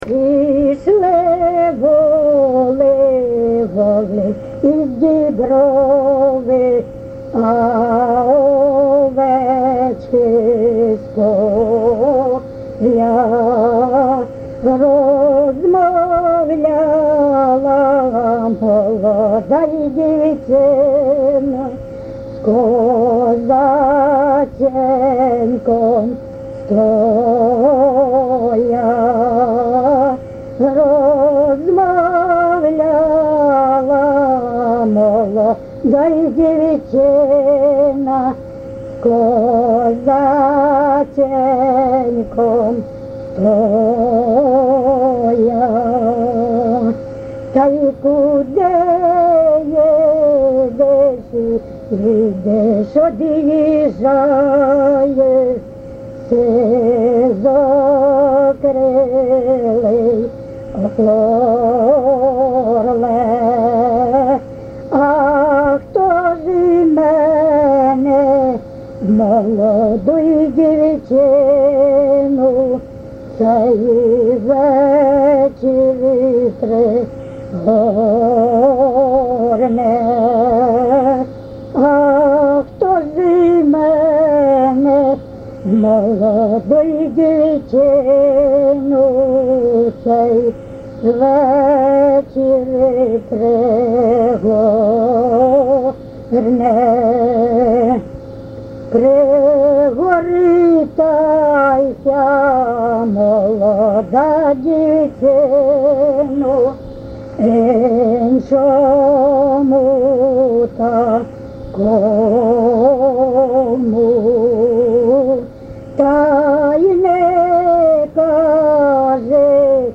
ЖанрПісні з особистого та родинного життя, Козацькі
Місце записус. Коржі, Роменський район, Сумська обл., Україна, Слобожанщина